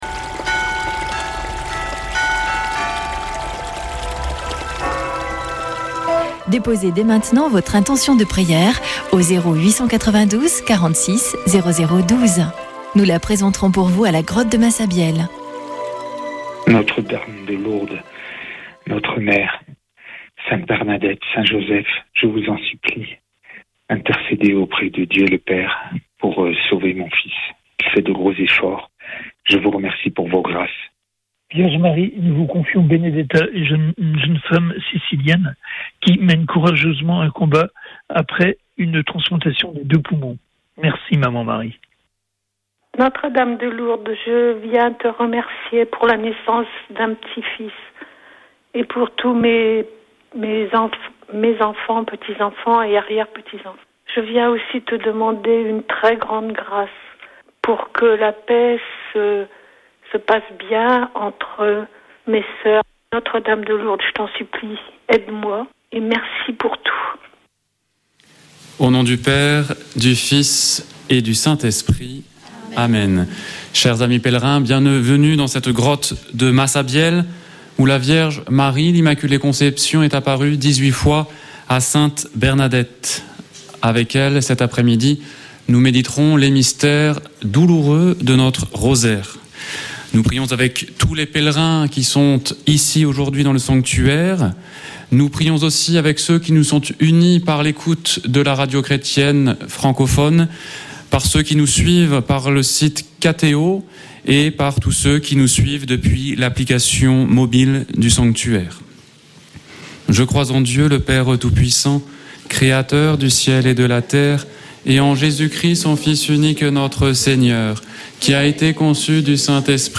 Chapelet de Lourdes du 16 janv.
Accueil \ Emissions \ Foi \ Prière et Célébration \ Chapelet de Lourdes \ Chapelet de Lourdes du 16 janv.
Une émission présentée par Chapelains de Lourdes